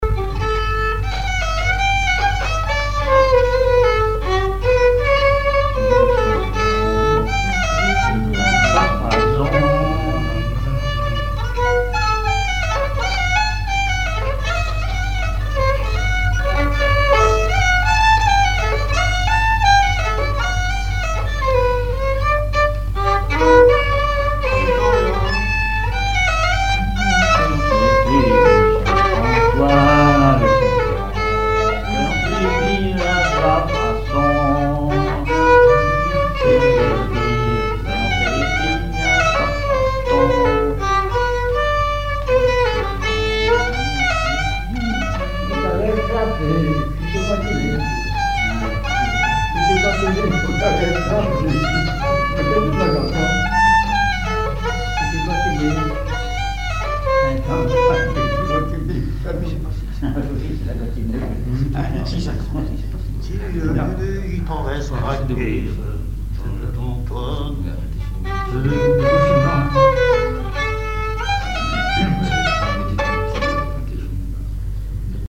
Mémoires et Patrimoines vivants - RaddO est une base de données d'archives iconographiques et sonores.
Chants brefs - A danser Résumé : Saint-Antoine avec son violon, fait danser le filles, fait danser les filles.
scottich trois pas
chansons et instrumentaux